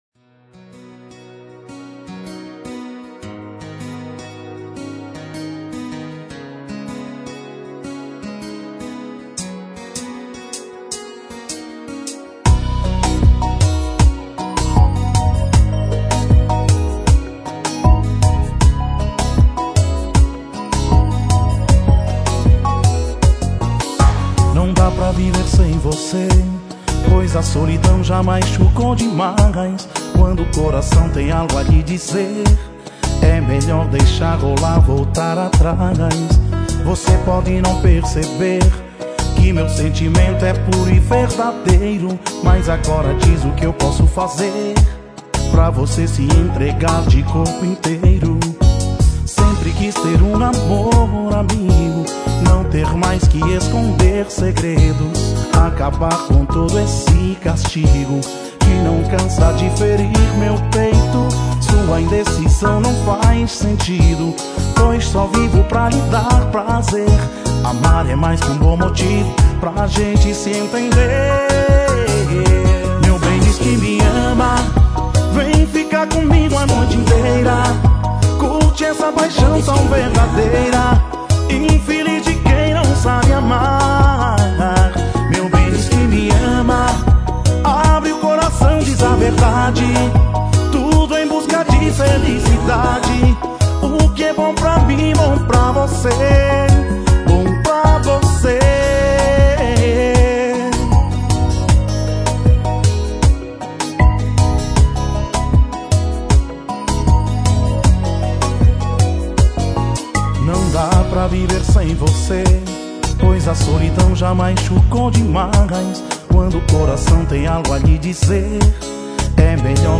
Kizomba Para Ouvir: Clik na Musica.